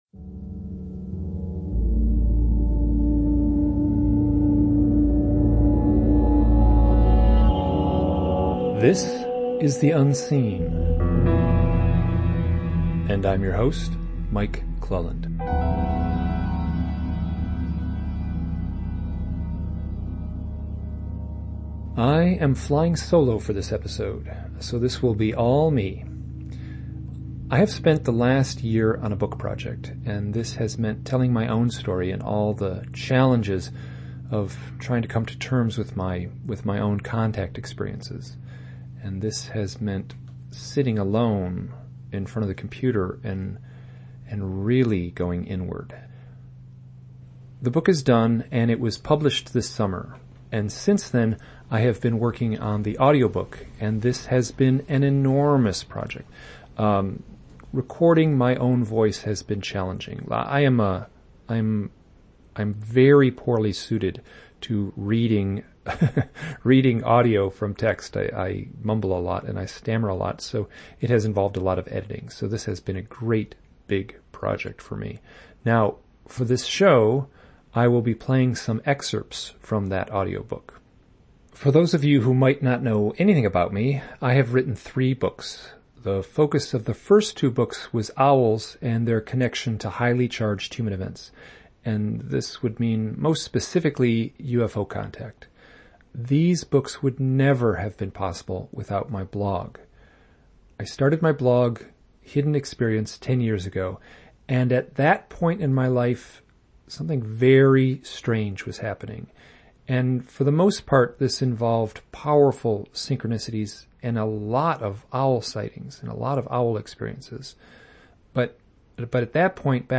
Audio Book Excerpts from Hidden Experience